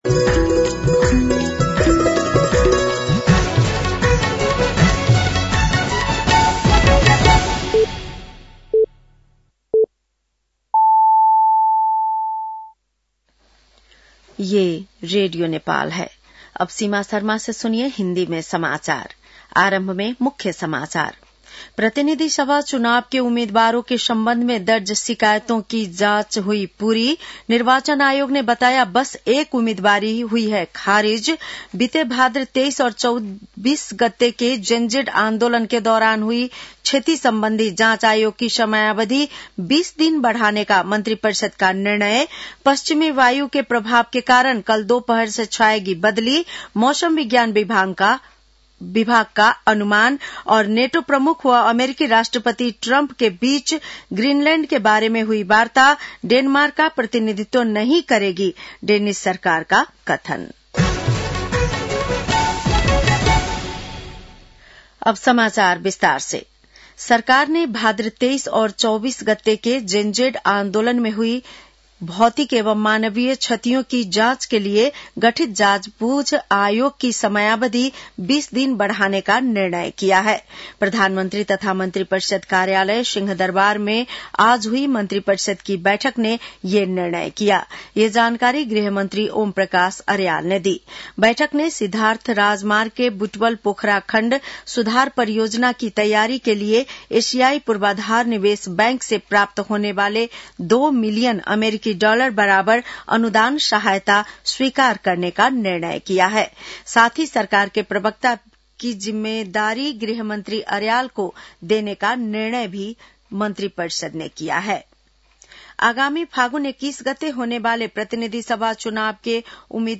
बेलुकी १० बजेको हिन्दी समाचार : ८ माघ , २०८२
10-PM-Hindi-NEWS-1-1.mp3